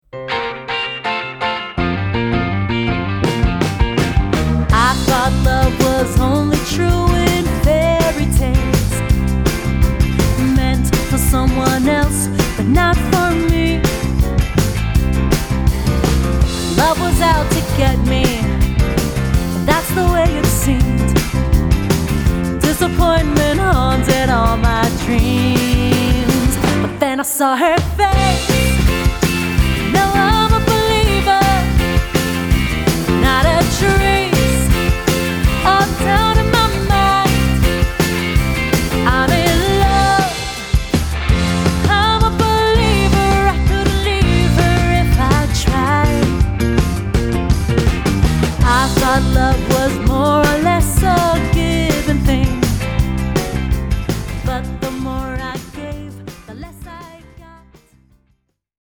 Quintett
Exzellente Livemusik für ihre Party.